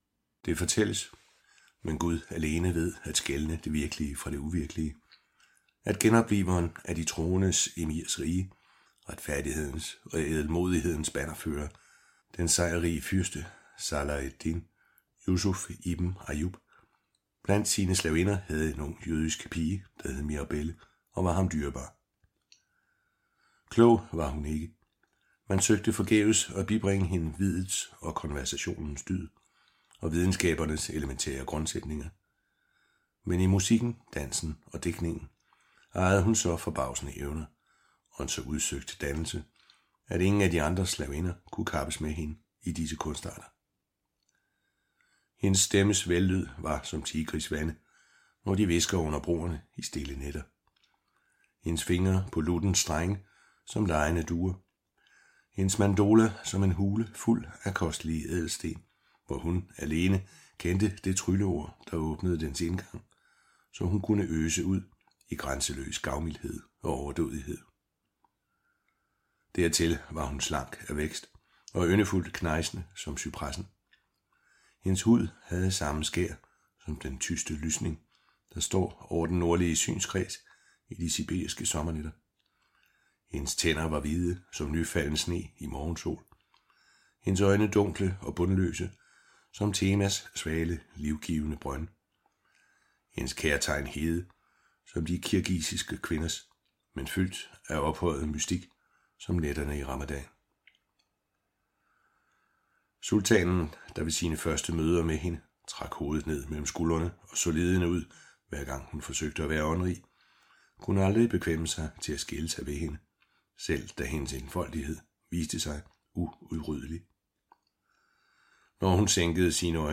Hør et uddrag af Magtens nat Magtens nat Korsfarer-trilogien bind 2 Format MP3 Forfatter Poul Hoffmann Lydbog E-bog 99,95 kr.